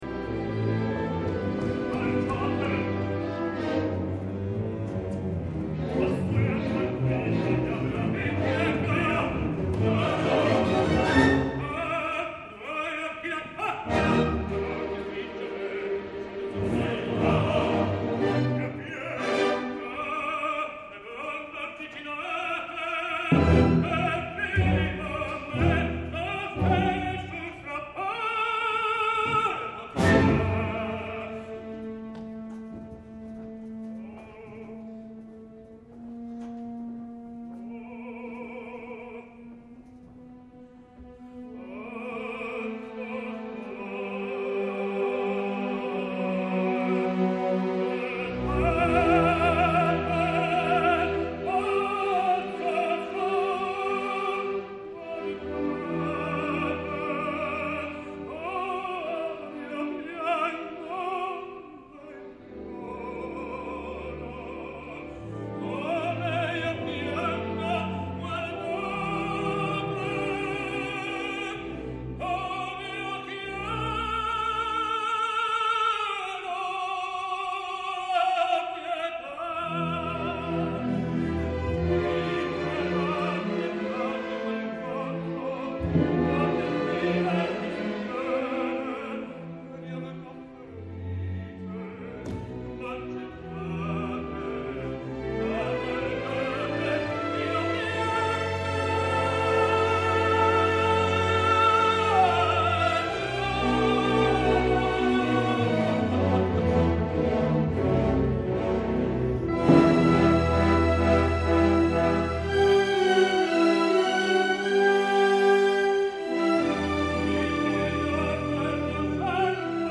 : I brani qui presentati sono tutti tratti da  registrazioni amatoriali , spesso realizzate da amici o colleghi Ne  è vietata la divulgazione con qualsiasi mezzo o utilizzo a  scopo commerciale.
Here below you can find some arias performed by  tenor  Antonello  Palombi.